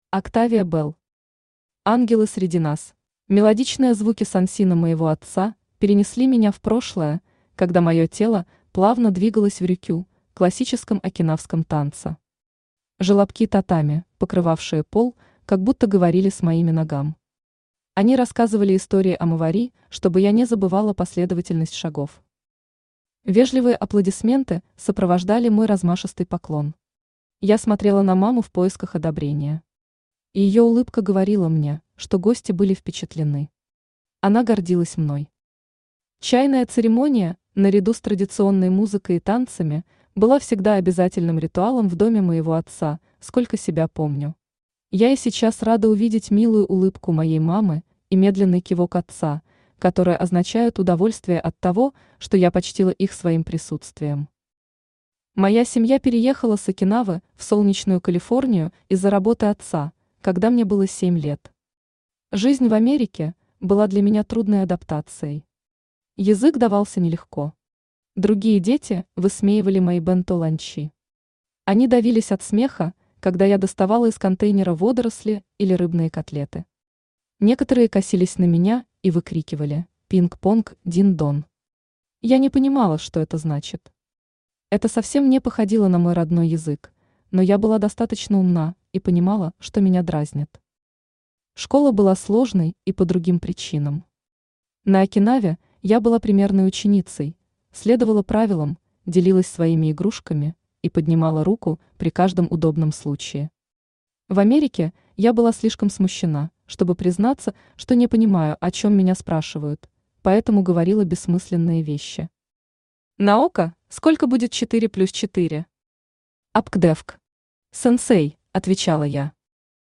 Аудиокнига Ангелы среди нас | Библиотека аудиокниг
Aудиокнига Ангелы среди нас Автор Октавия Белл Читает аудиокнигу Авточтец ЛитРес.